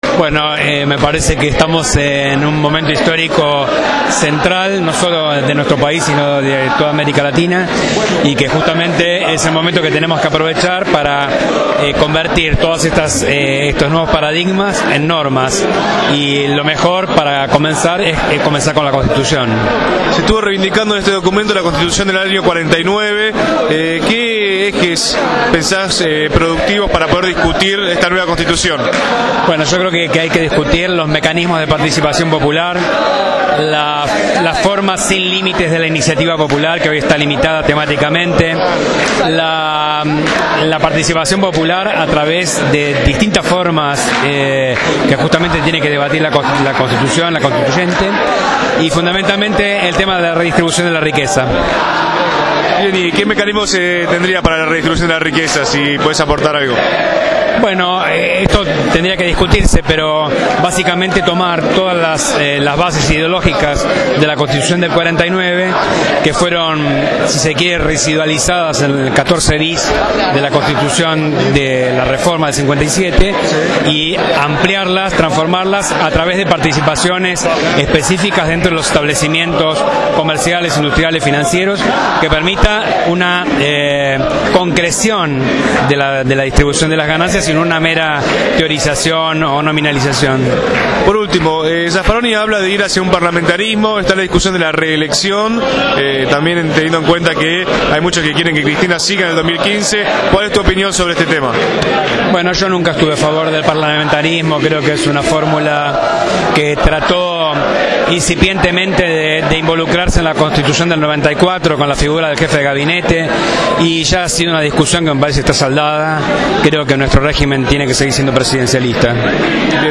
Dr. Roberto Andrés Gallardo, Defensor General Adjunto en lo Penal , Contravencional y de Faltas de la Ciudad Autónoma de Buenos Aires.